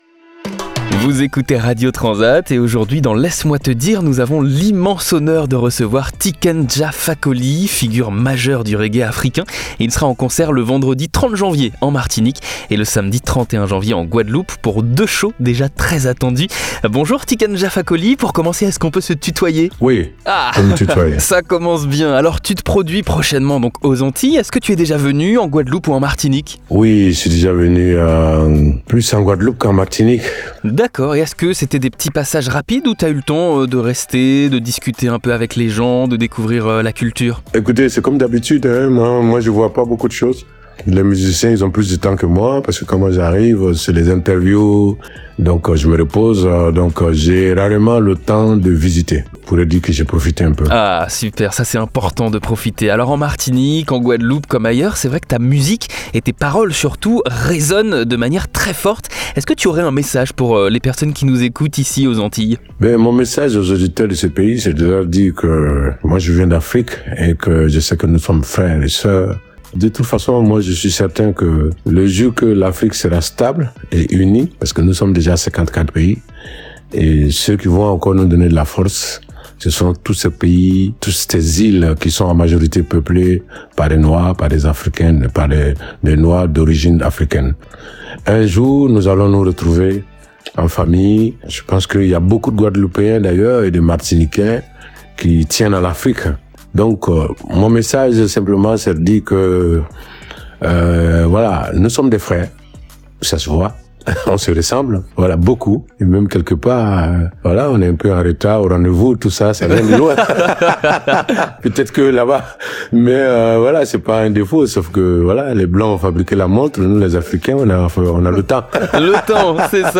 Dans cette interview, Tiken Jah Fakoly nous parle de son rapport aux Antilles, de l'unité des peuples Africains et ultramarins, et nous invite à ses concerts TRANSAT le 30 Janvier en Martinique, et le 31 Janvier en Guadeloupe.